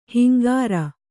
♪ hingāra